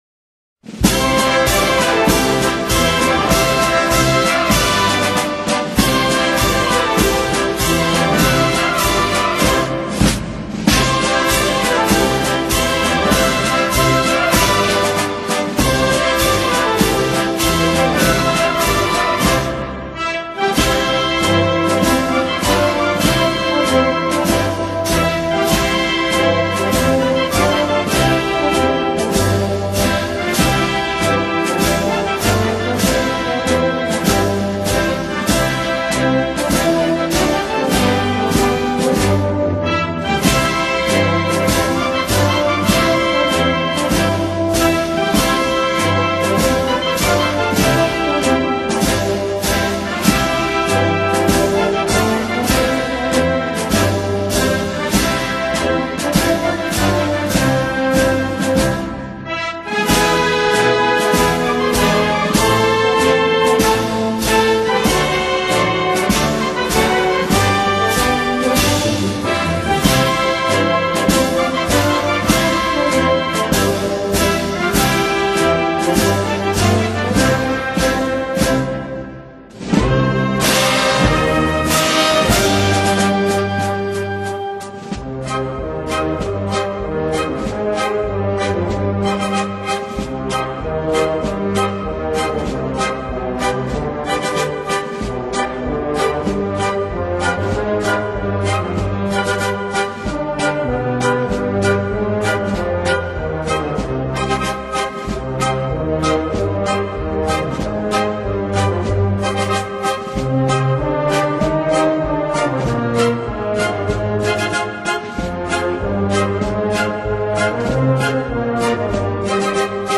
XXXIII acto de Exaltación a Nuestra Señora de la Encarnación